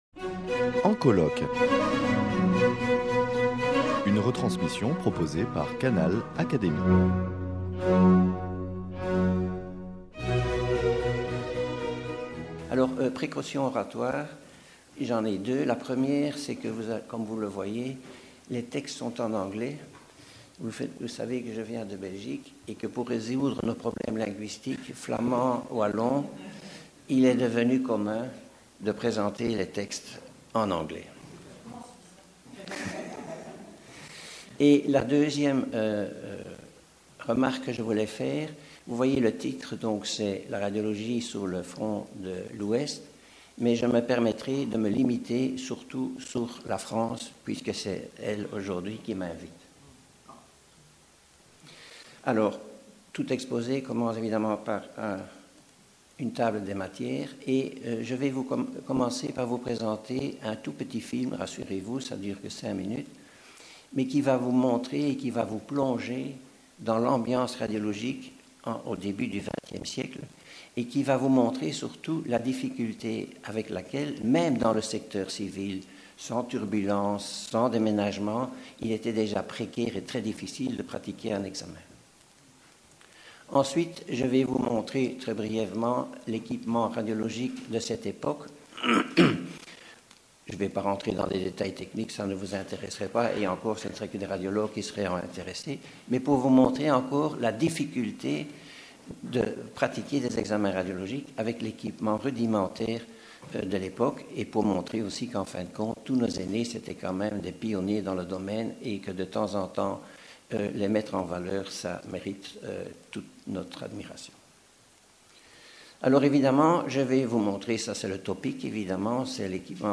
prononcée le 10 décembre 2015 lors des journées d’étude « Guerre et santé »